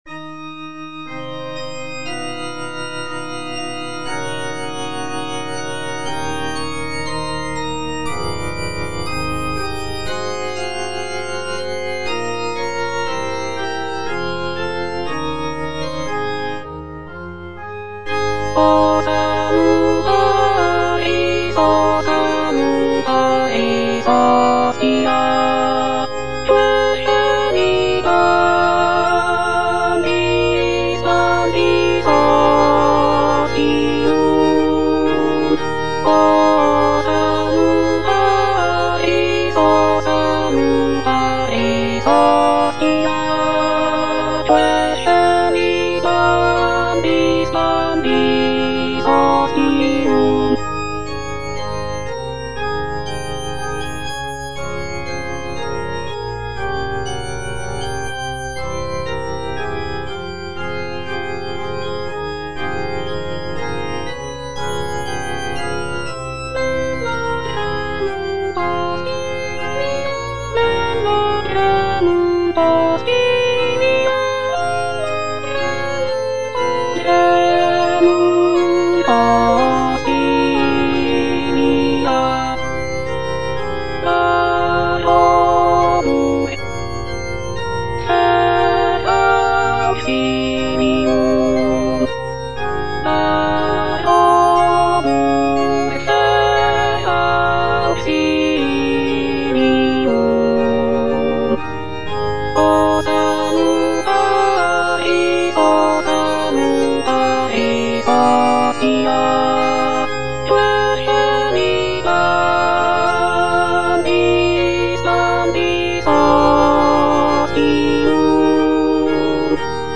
G. FAURÉ, A. MESSAGER - MESSE DES PÊCHEURS DE VILLERVILLE O salutaris - Alto (Emphasised voice and other voices) Ads stop: auto-stop
The composition is a short and simple mass setting, featuring delicate melodies and lush harmonies.